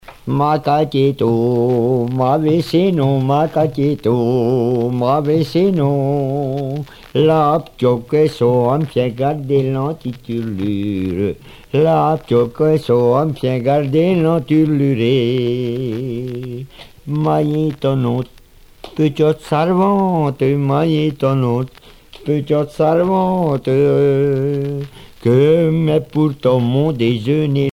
Genre laisse
Chanteurs et musiciens de villages en Morvan
Pièce musicale éditée